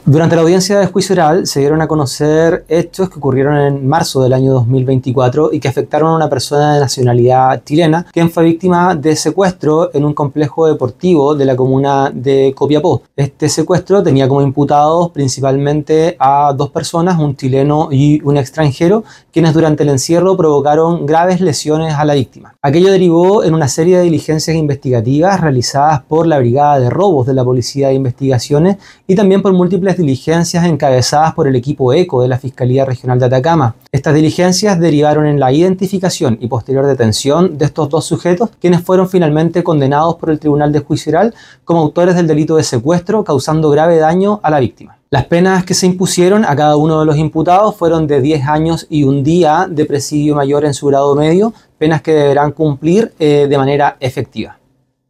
Fiscal Pedro Pablo Orellana informó que dos imputados indagados por su participación en este grave delito fueron condenados en audiencia de Juicio Oral.
AUDIO-FISCAL-PEDRO-PABLO-ORELLANA.mp3